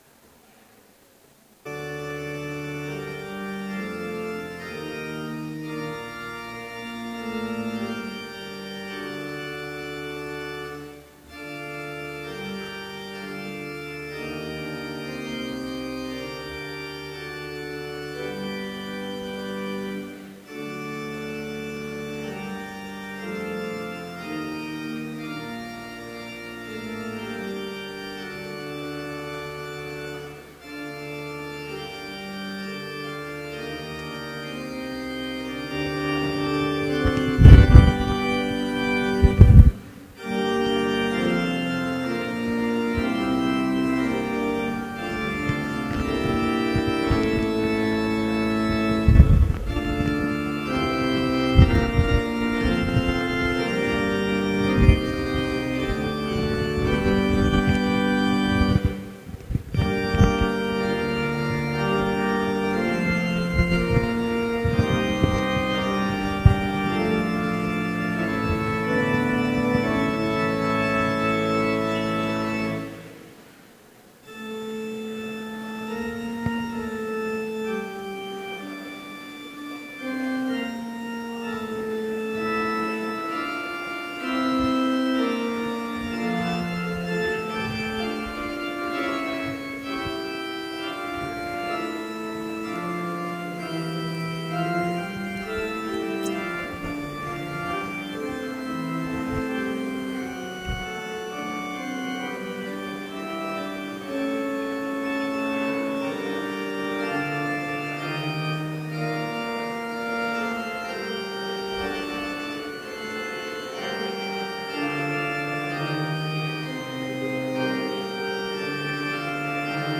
Complete service audio for Chapel - October 26, 2015